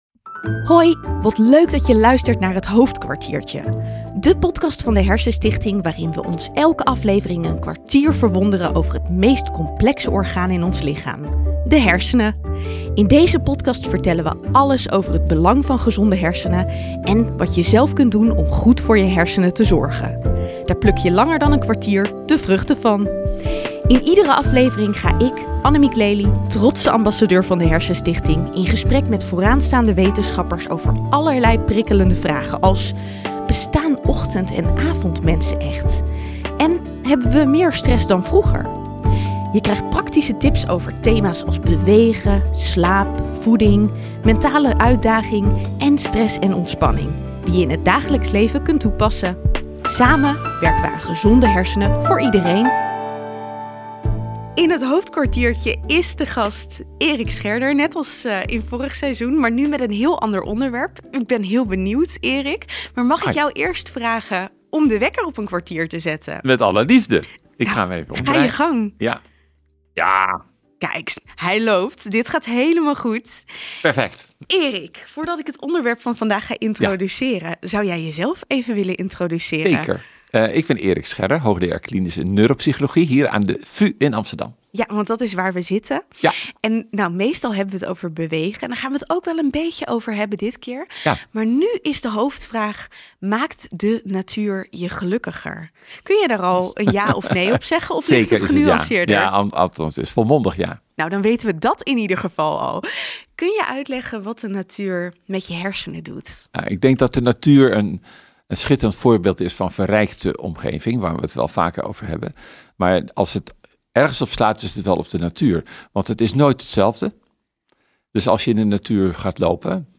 Tijd doorbrengen in de natuur of een groene omgeving heeft een positief effect op je hersenen. Hersenwetenschapper Erik Scherder geeft een duidelijk antwoord op de hoofdvraag van deze aflevering: alleen al het kijken naar groen of het maken van een korte wandeling in een natuurlijke omgeving kan stress verlagen, stemming verbeteren en concentratie versterken. Hij legt uit hoe dit precies werkt, welke hersengebieden hierbij betrokken zijn en hoe we deze kennis in ons dagelijks leven kunnen toepassen.